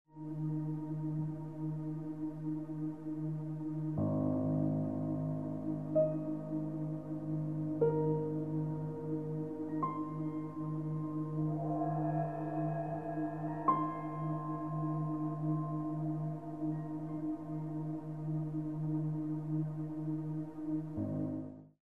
CD of Modern Dance Class Music